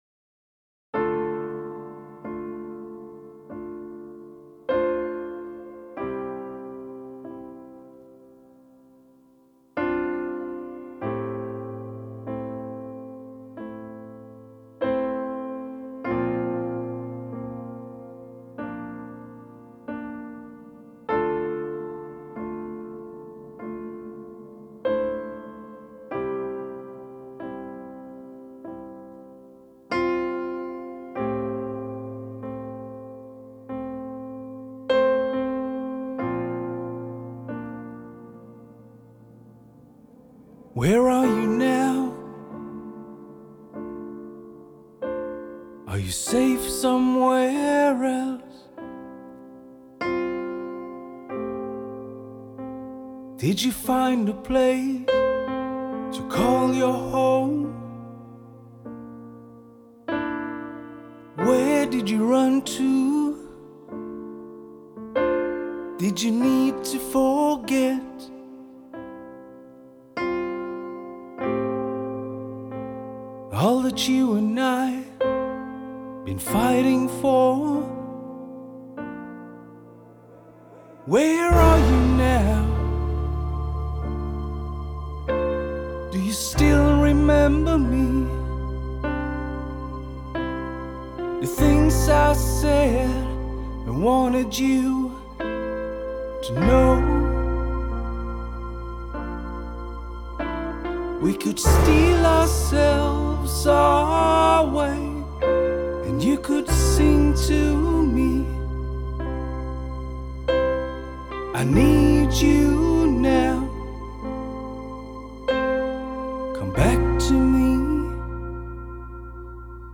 Genre: Progressive Rock
progressive rock